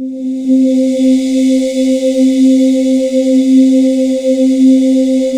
Index of /90_sSampleCDs/USB Soundscan vol.28 - Choir Acoustic & Synth [AKAI] 1CD/Partition C/07-DEEEP